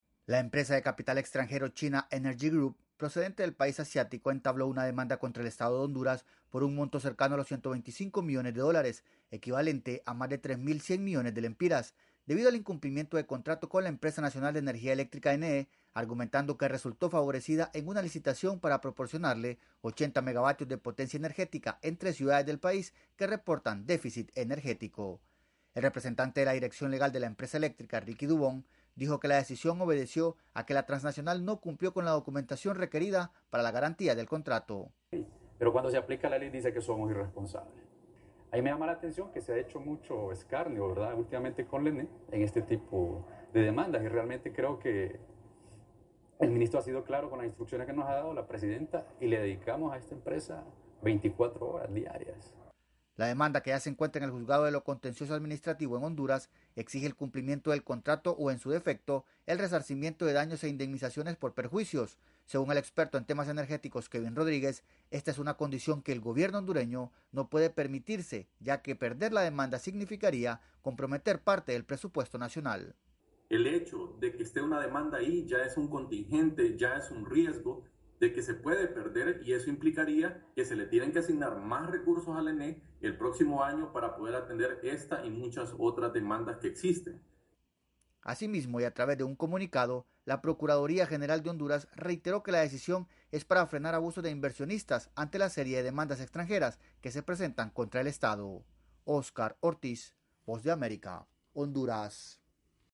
Una empresa de inversiones energéticas procedente de China demandó al gobierno de Honduras por 125 millones de dólares alegando incumplimiento de contrato. Desde Honduras informa el corresponsal de la Voz de América